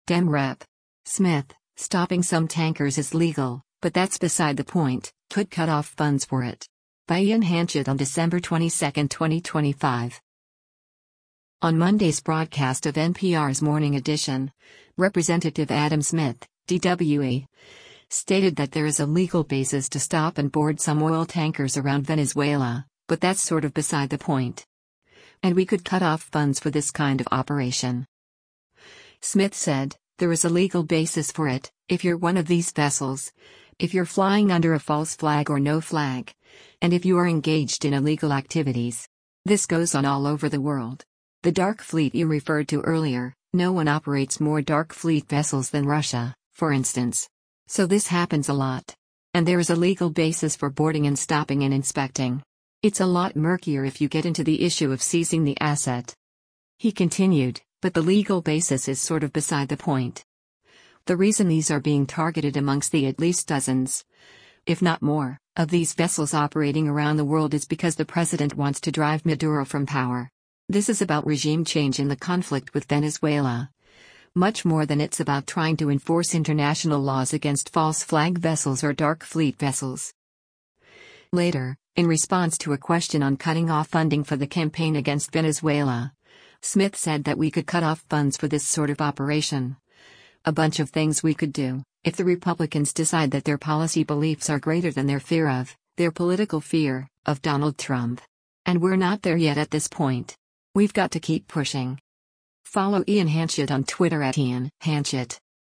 On Monday’s broadcast of NPR’s “Morning Edition,” Rep. Adam Smith (D-WA) stated that there is a legal basis to stop and board some oil tankers around Venezuela, but that’s “sort of beside the point.” And “we could cut off funds for” this kind of operation.